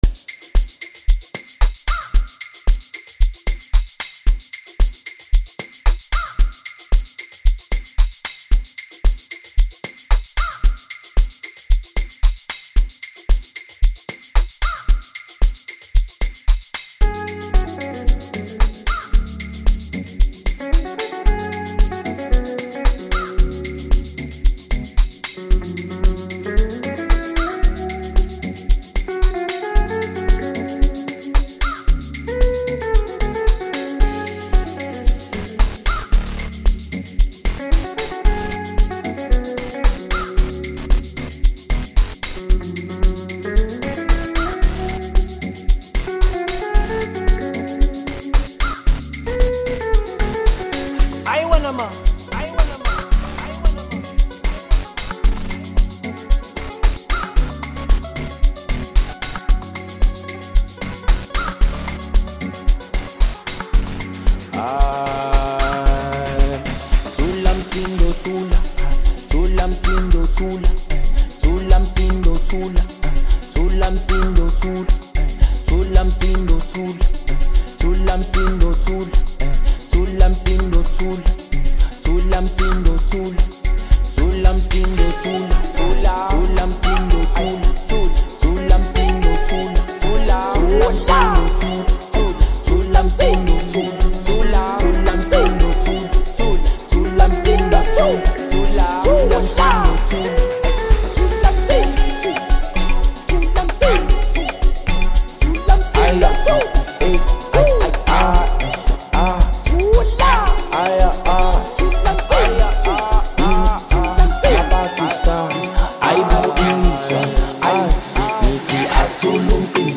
Home » Amapiano